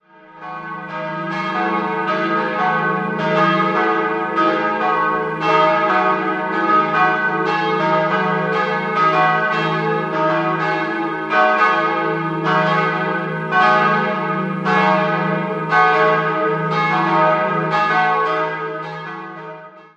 3-stimmiges TeDeum-Geläute: dis'-fis'-gis' Die Glocken 1 und 3 wurden 1899 von der Firma Otto in Bremen-Hemelingen gegossen, die mittlere stammt aus spätgotischer Zeit und wurde 1514 von Johann von Andernach (Cöln) gegossen.